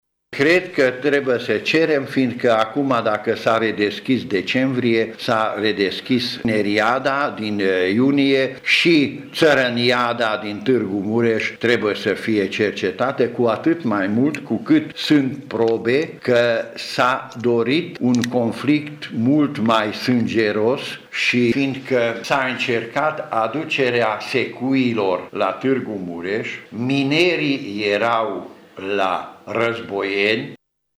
Avocatul mureşan a spus azi, intr-o conferință de presă, că, în opinia sa, există elementele necesare pentru a se cere analizarea celor petrecute la Tîrgu-Mureş în primăvara anului 1990.